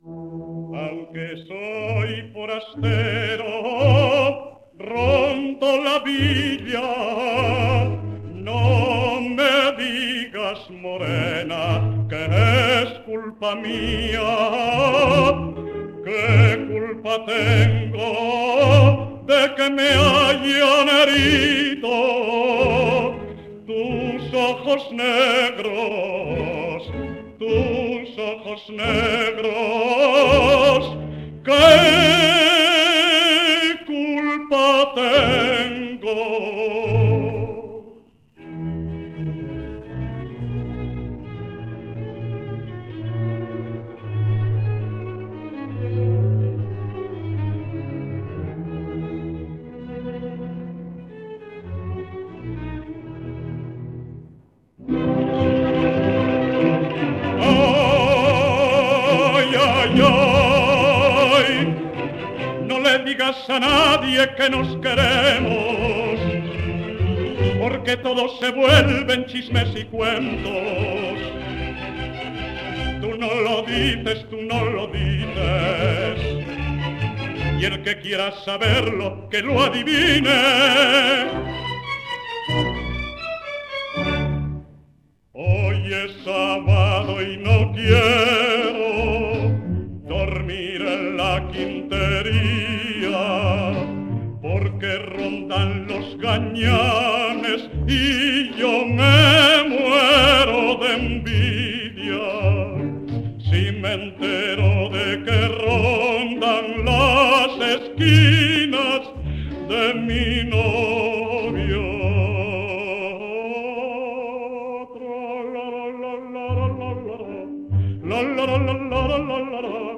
78 rpm